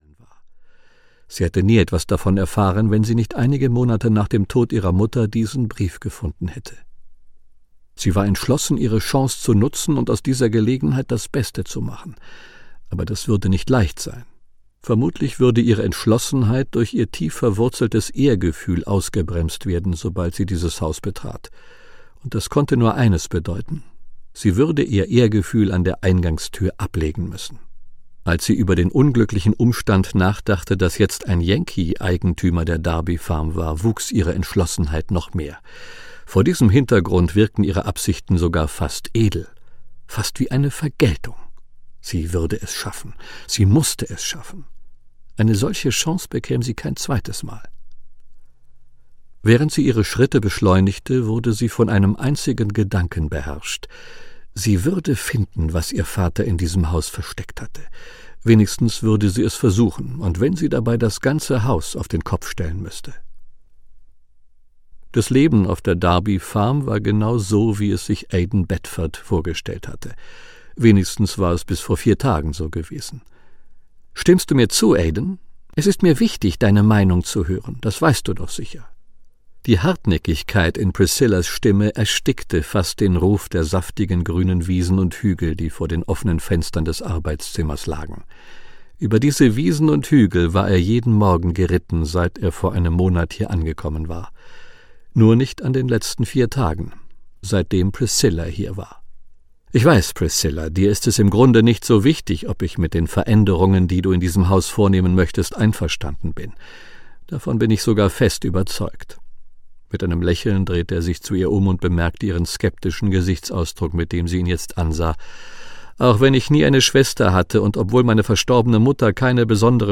Hörbuch Savannah, Tamera Alexander.